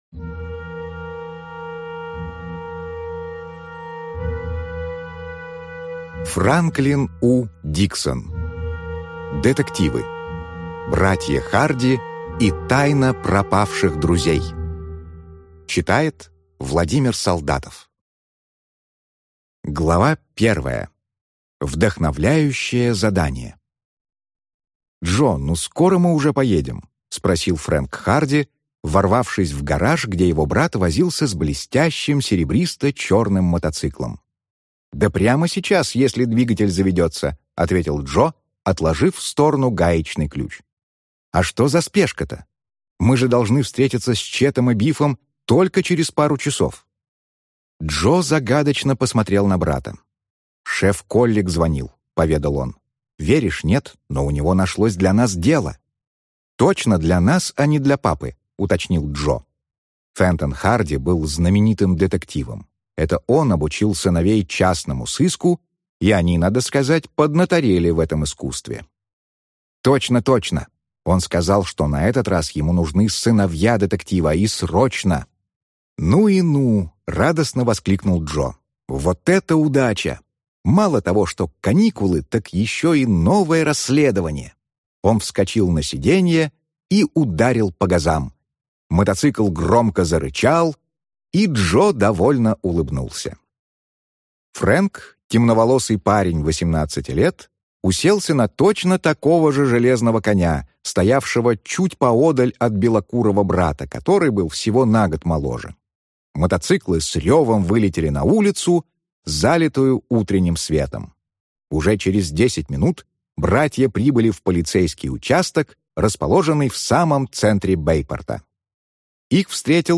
Аудиокнига Братья Харди и тайна пропавших друзей | Библиотека аудиокниг